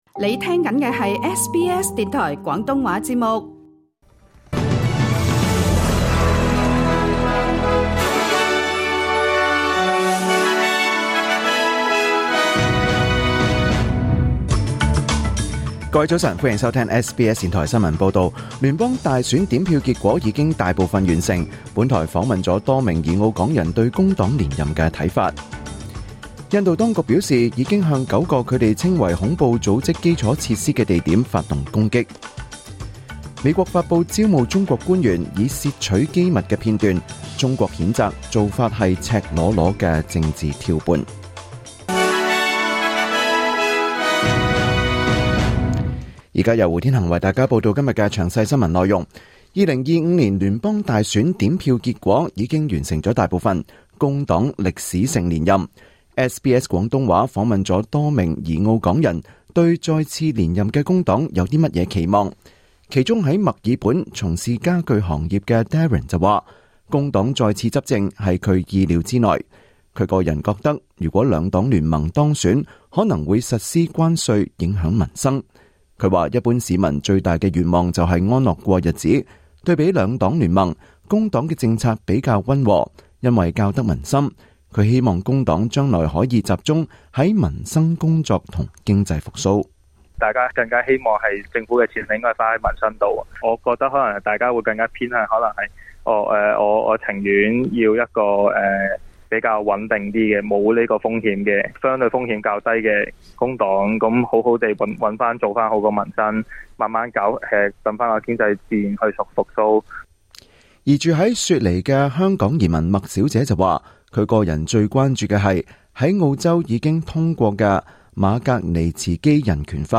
2025年5月7日SBS 廣東話節目九點半新聞報道。